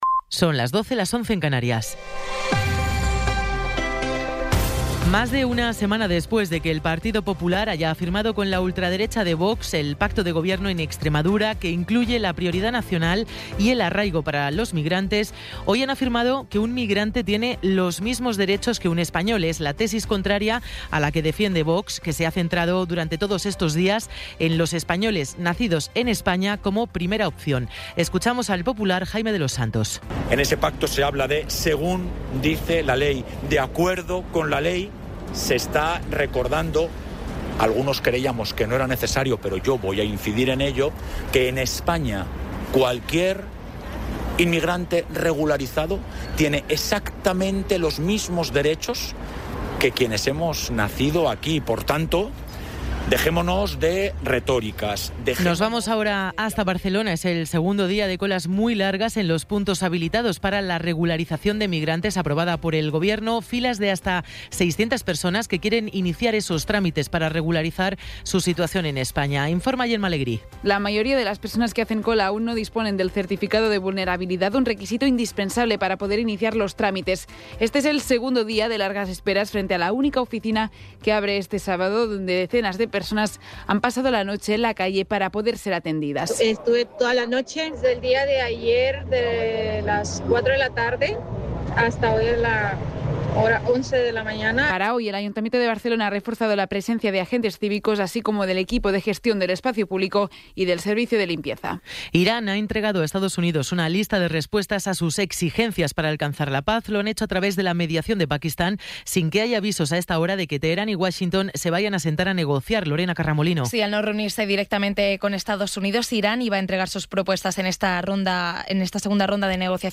Resumen informativo con las noticias más destacadas del 25 de abril de 2026 a las doce.